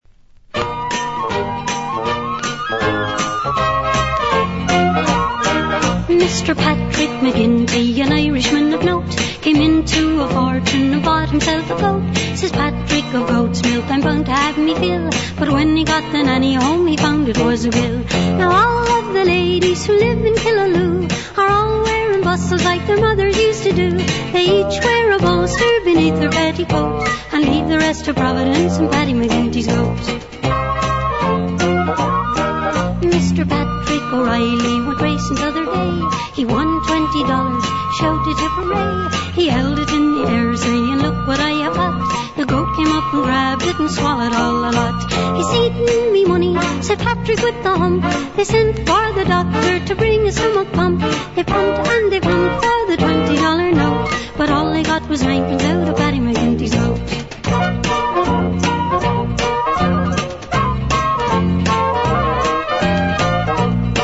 (vinyl)